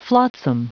Prononciation du mot flotsam en anglais (fichier audio)
Prononciation du mot : flotsam